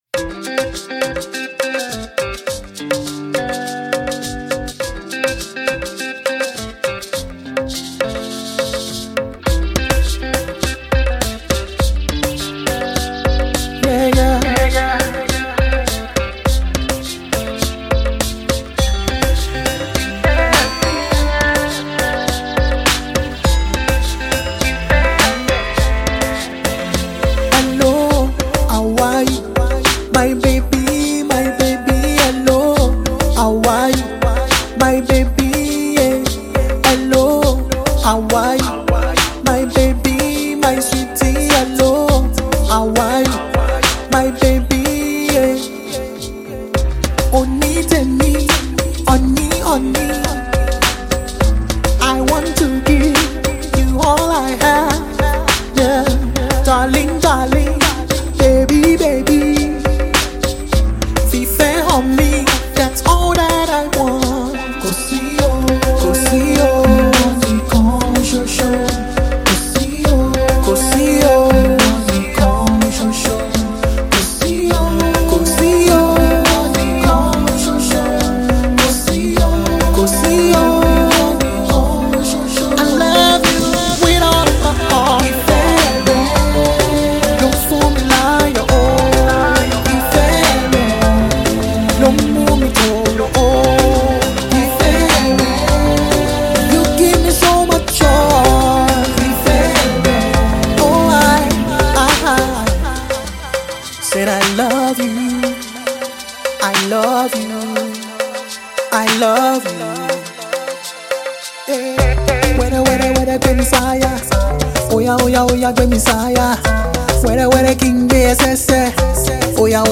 Blues, Yoruba Music
love song